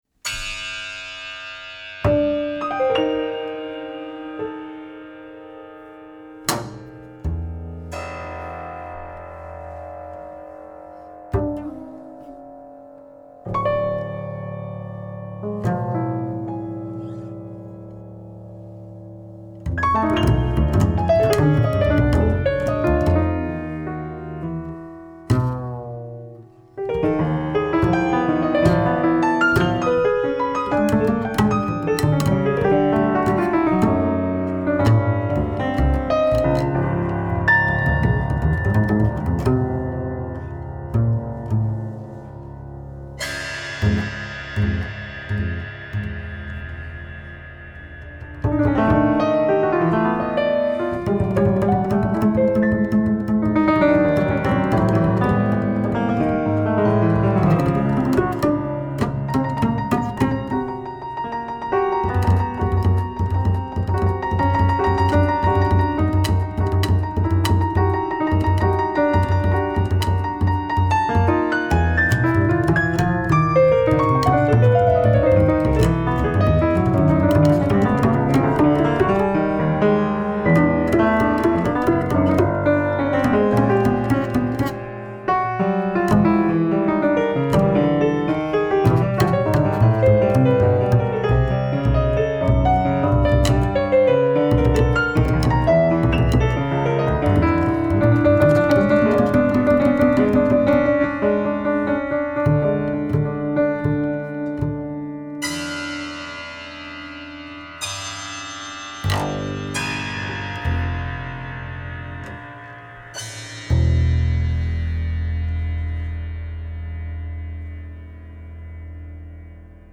double bass
prepared and unprepared piano